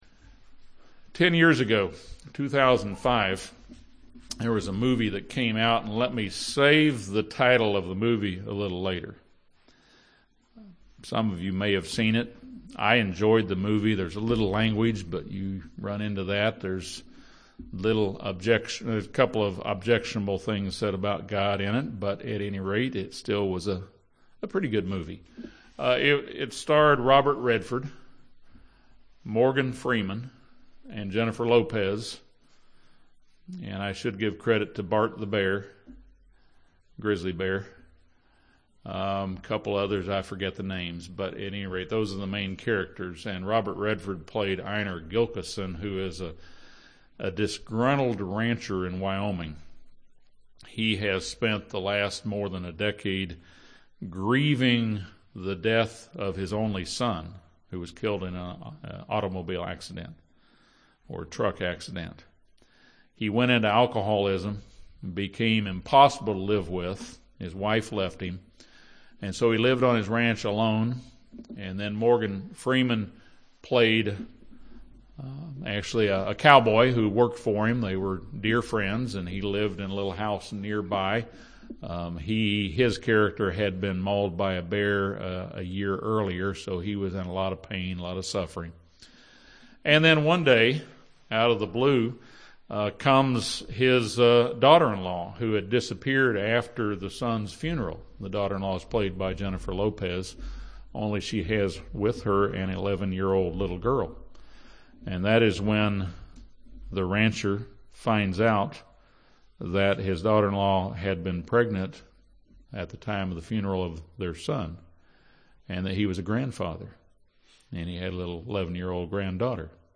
This sermon discusses the meaning of the 8th Day of the Feast.